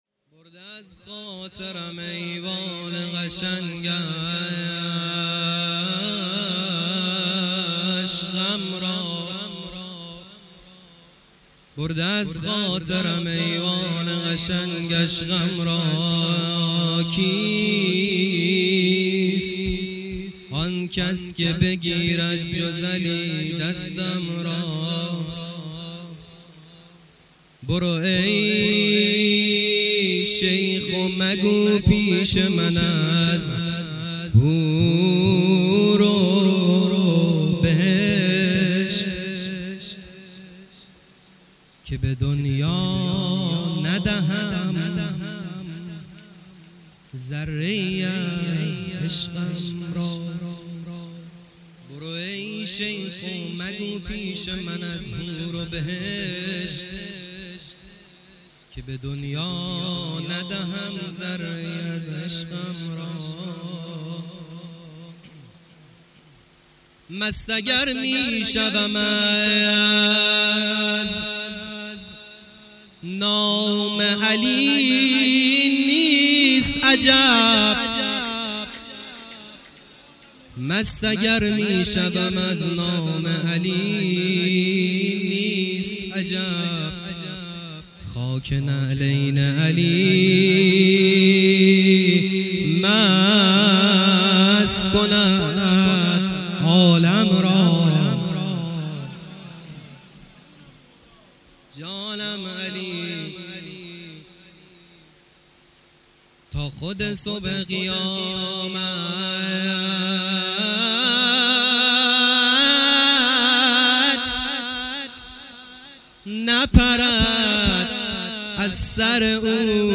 مدح - برده از خاطرم ایوان قشنگش غم را